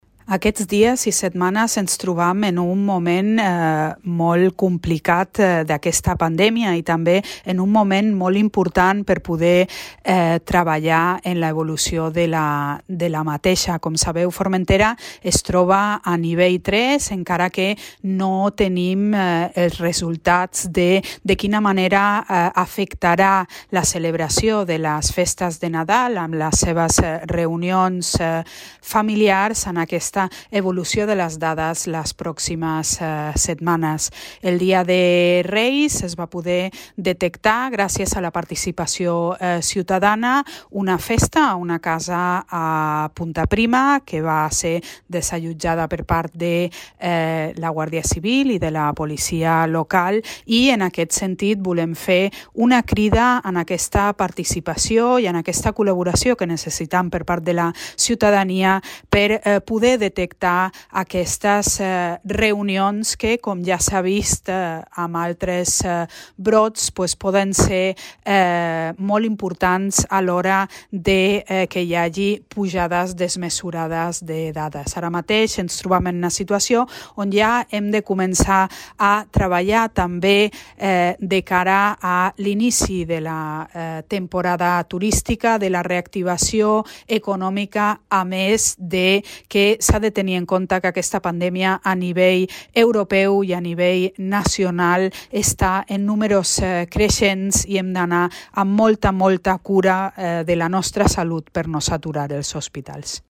En el següent àudio podeu escoltar la crida feta per la presidenta del Consell de Formentera, Alejandra Ferrer